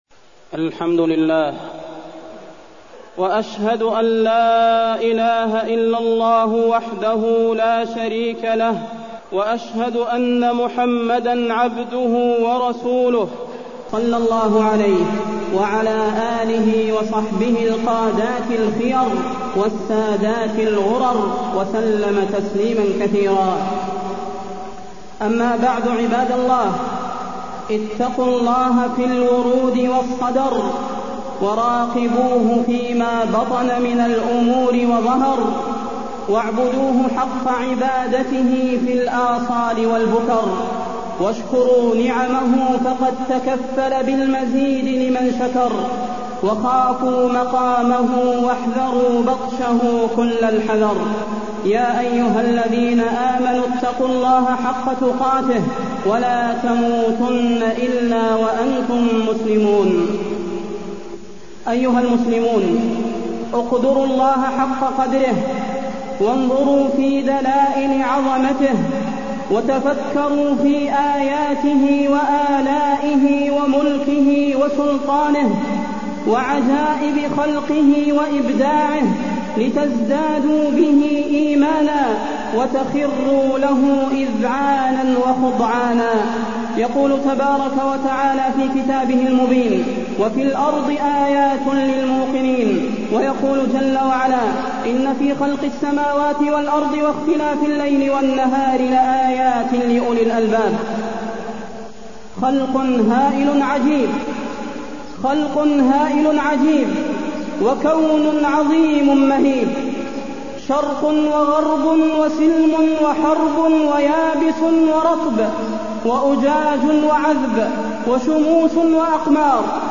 تاريخ النشر ٣ صفر ١٤٢٢ هـ المكان: المسجد النبوي الشيخ: فضيلة الشيخ د. صلاح بن محمد البدير فضيلة الشيخ د. صلاح بن محمد البدير التوحيد